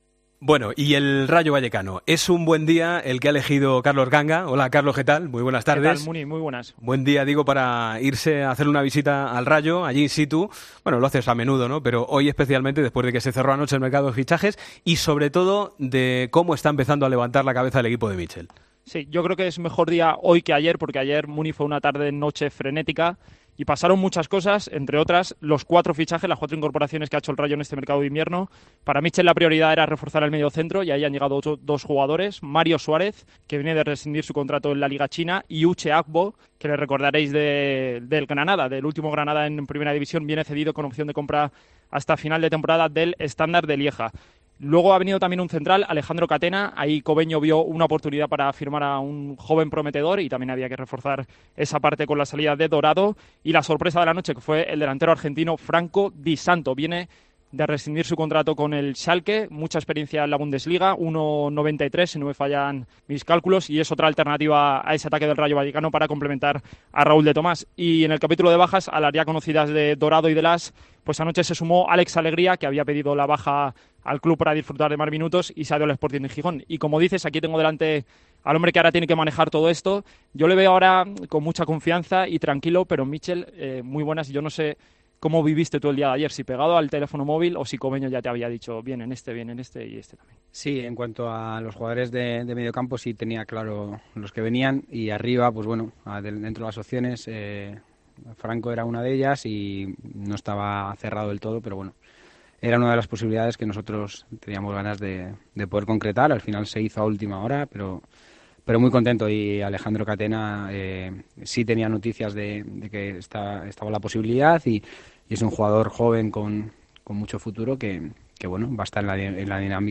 El técnico del Rayo habló en Deportes COPE del marcado de fichajes y la marcha de su equipo en LaLiga.